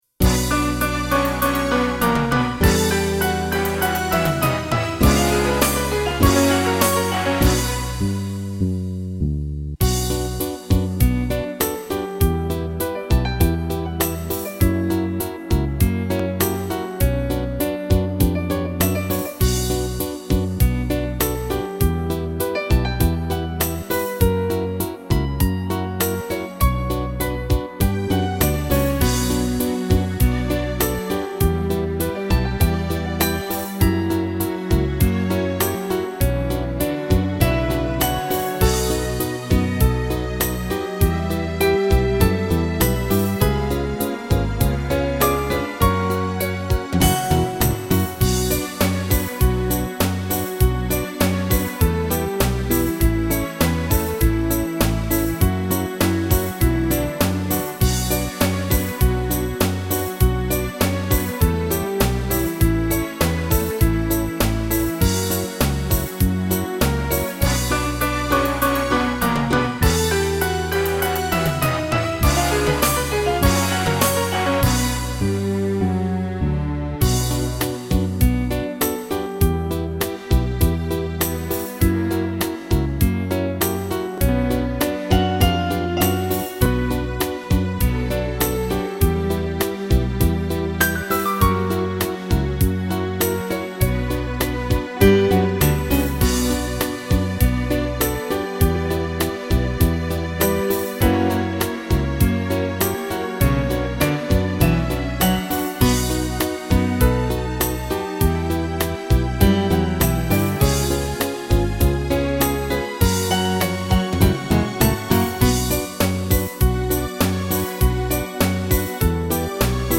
Минус Детские